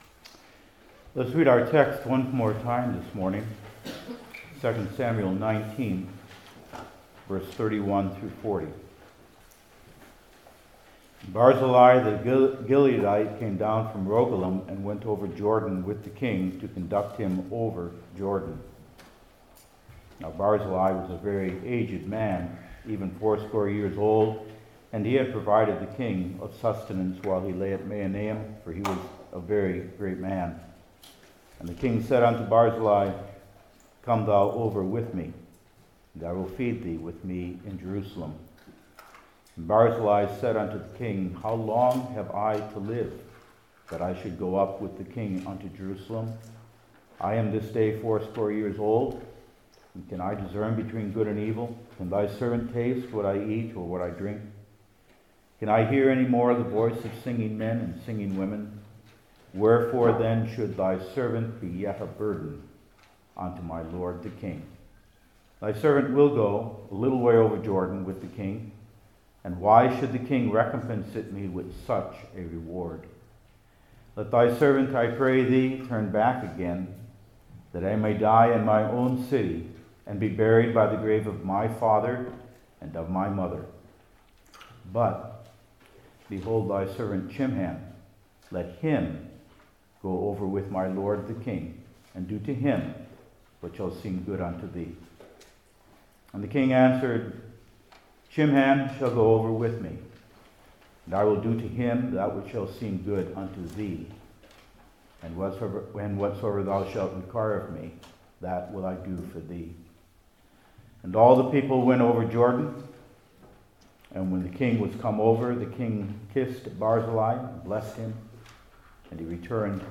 Old Testament Individual Sermons I. Barzillai’s Confession II.